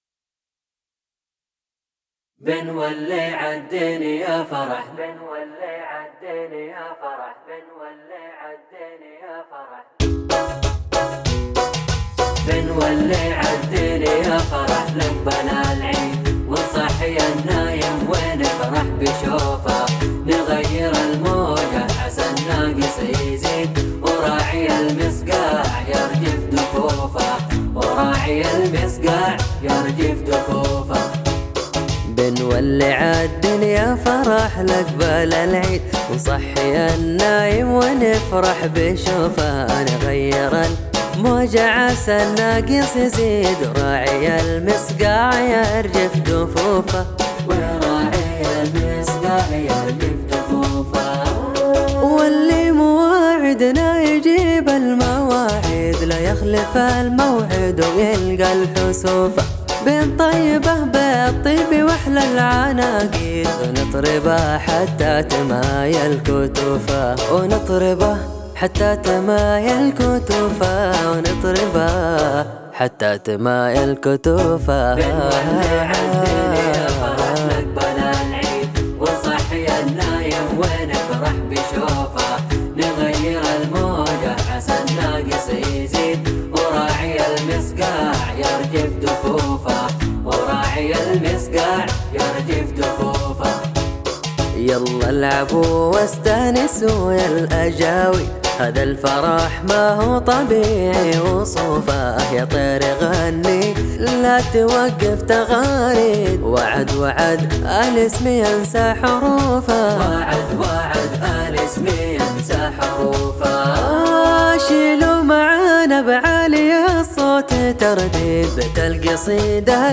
نسخة إيقاع بدون دخلات